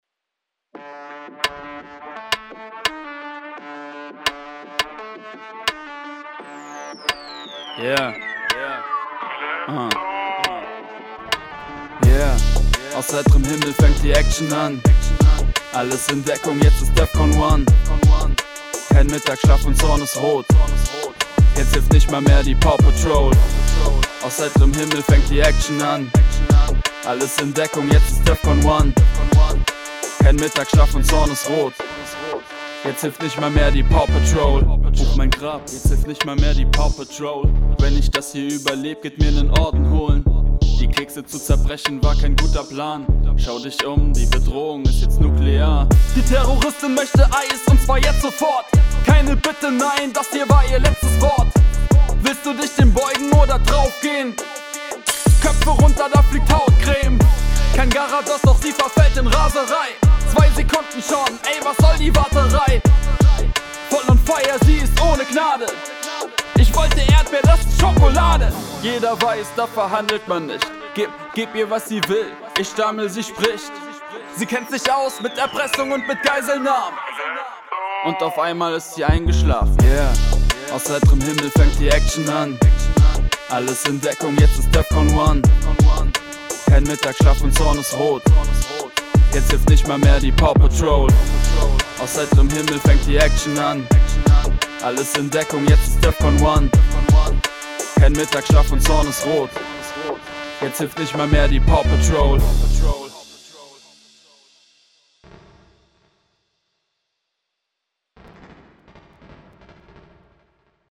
Merkwürdige Sound-Artefakte nach Export
Hallo liebes Forum, ich habe nach dem Export seltsame Artefakte auf meiner Stereospur, deren Herkunft ich nicht zuordnen kann.
Sie treten über die gesamte Zeit auf, am besten hört man sie aber am Ende.